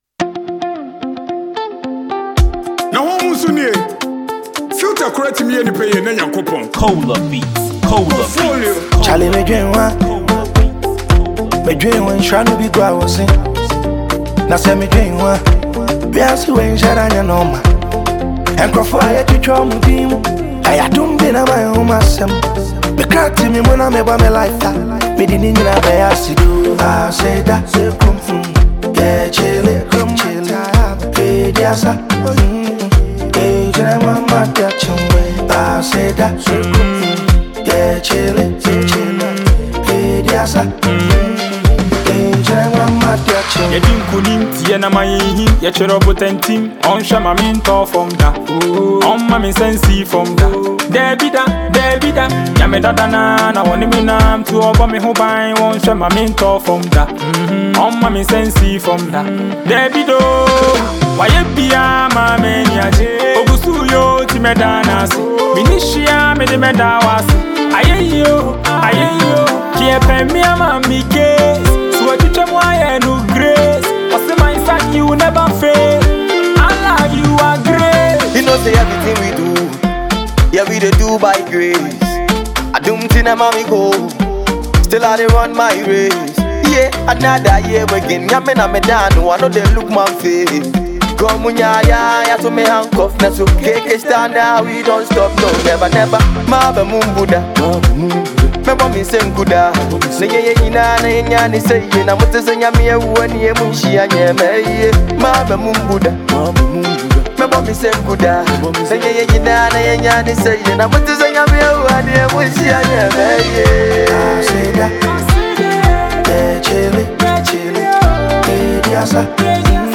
• Genre: Hip-Hop / Rap